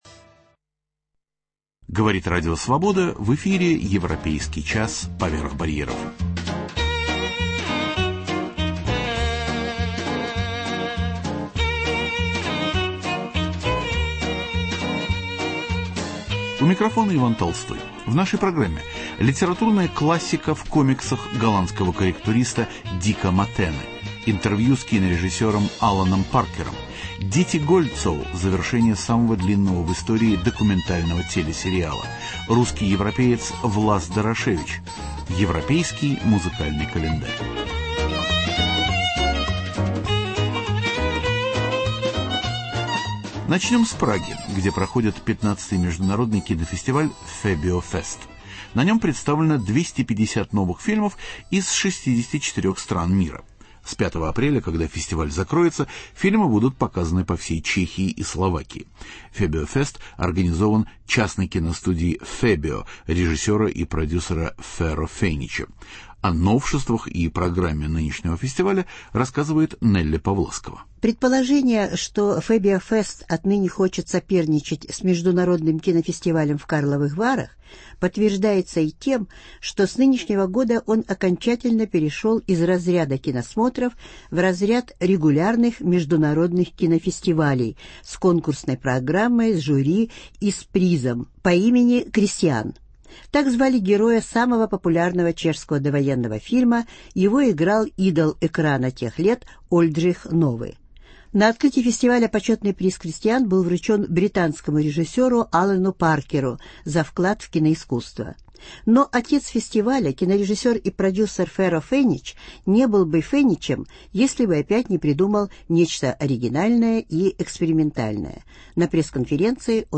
Интервью с кинорежиссером Аланом Паркером.